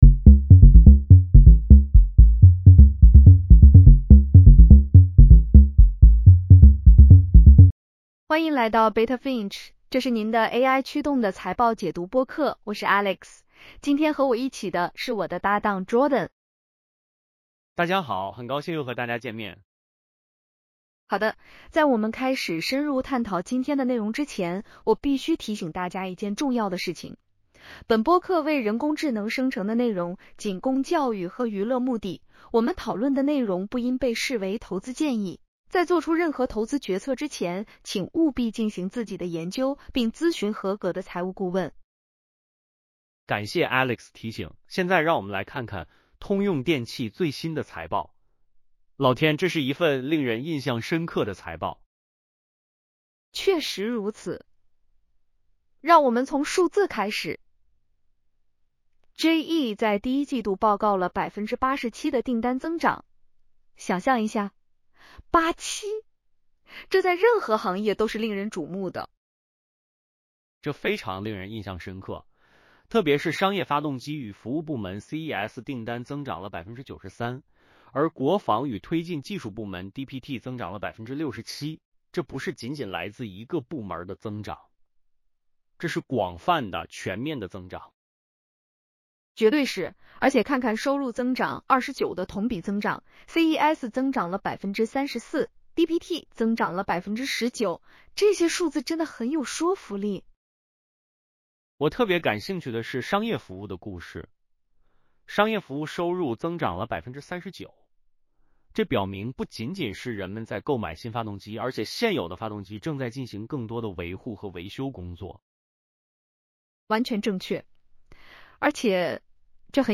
欢迎来到Beta Finch，这是您的AI驱动的财报解读播客。